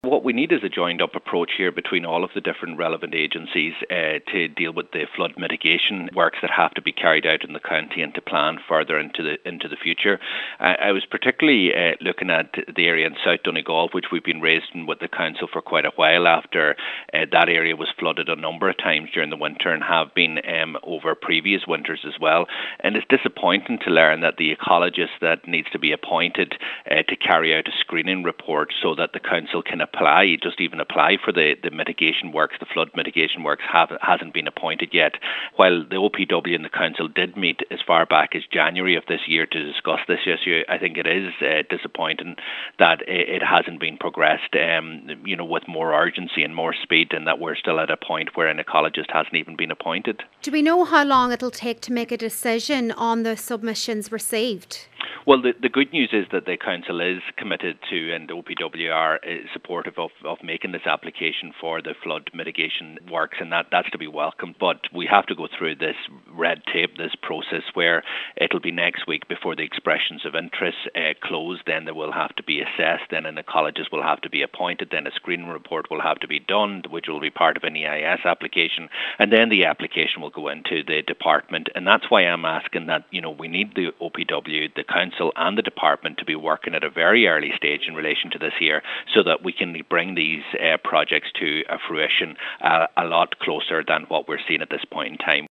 Donegal Deputy Pearse Doherty says these works are highly important, and further delay should be avoided……….